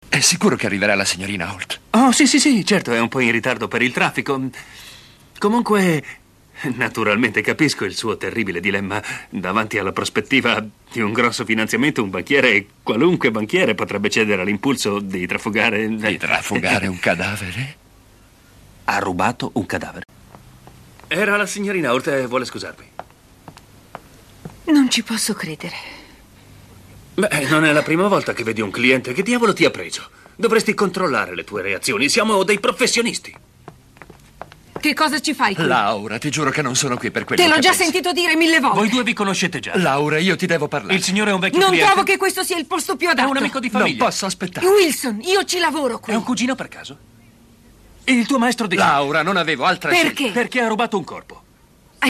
nel telefilm "Remington Steele", in cui doppia Pierce Brosnan.